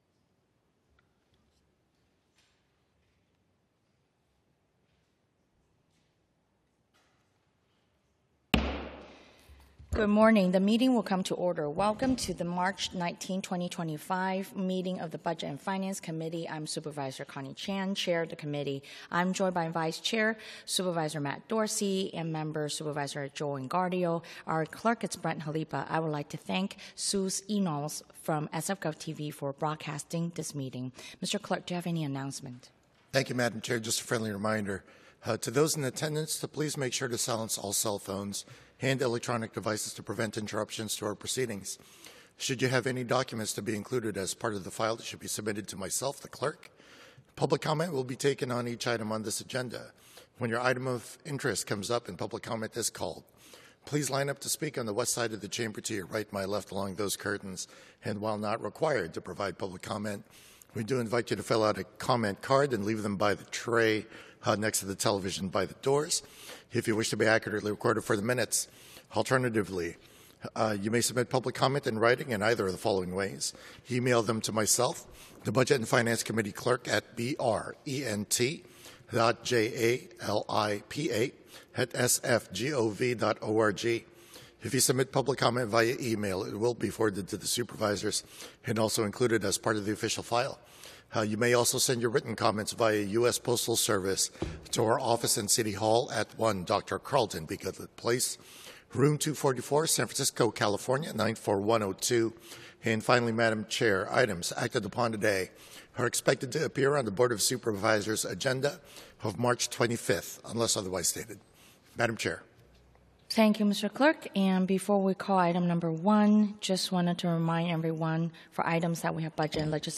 BOS Budget and Finance Committee - Regular Meeting - Mar 19, 2025